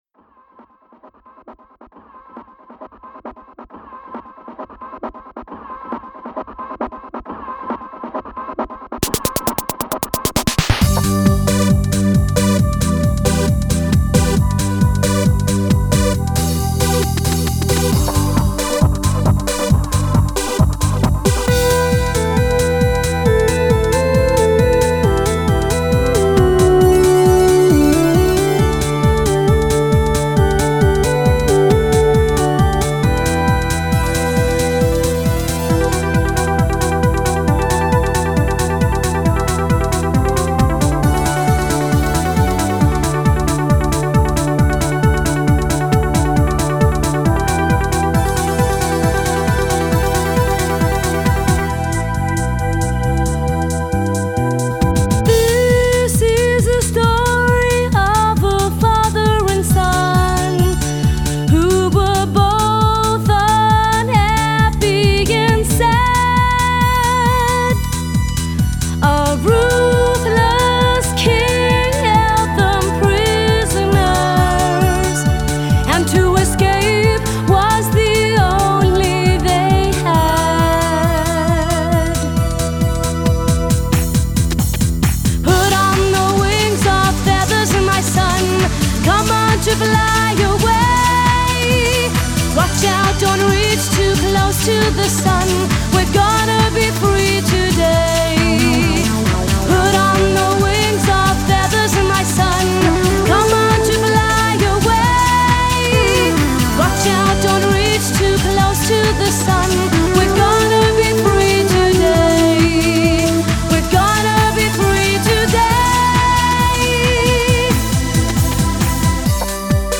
Ηλεκτρική Κιθάρα
Ακουστική Κιθάρα